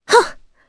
Laias-Vox_Landing_jp.wav